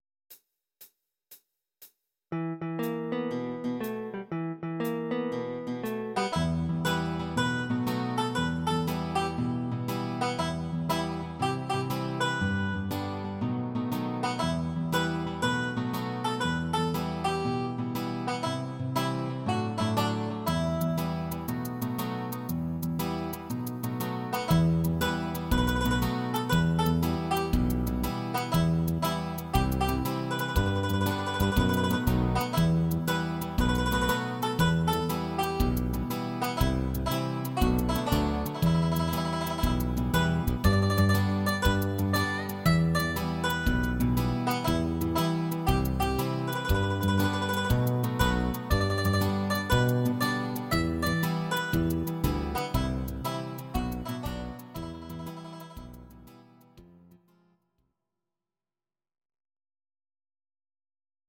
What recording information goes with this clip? These are MP3 versions of our MIDI file catalogue.